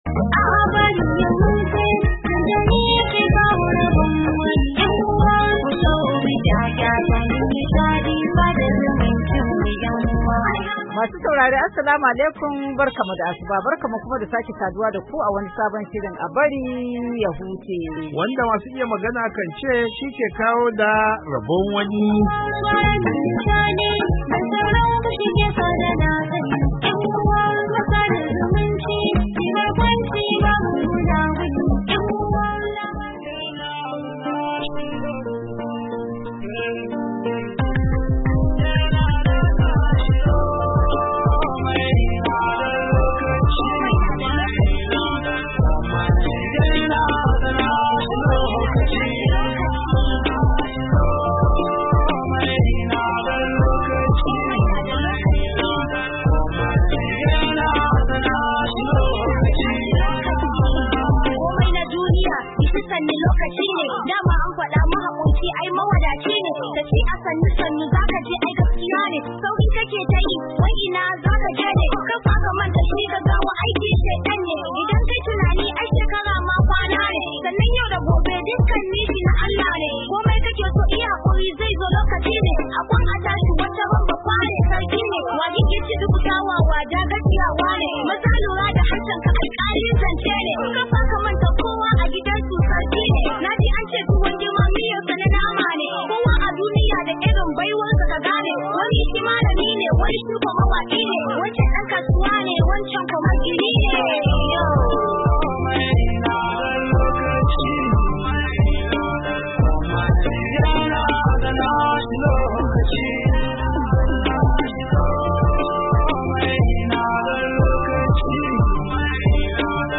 A shirin A Bari Ya Huce na wannan makon mun yi hira da fitaccen dan wasan finafinan Hausa da kuma marubuci Ali Nuhu kan sana'ar tasa. Mun kuma bada labarin wani Bafullatani da ya je sayen lemun kwalba.